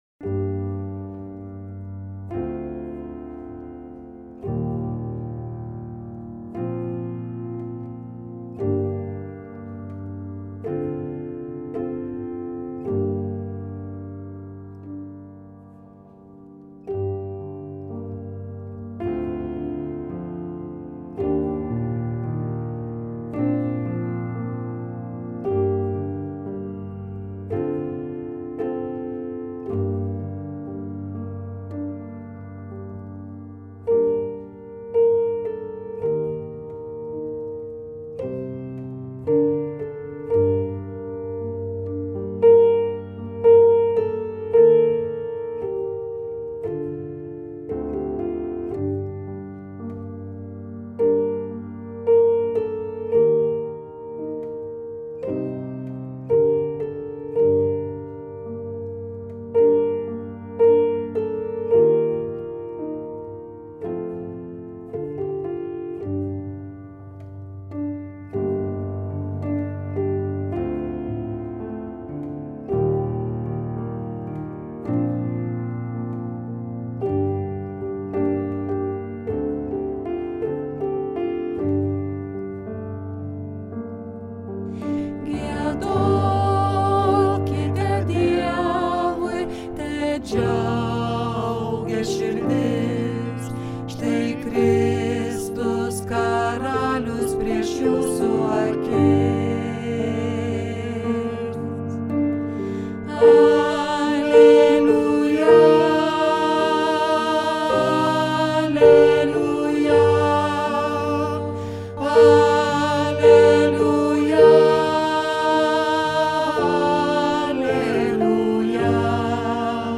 Tenoras: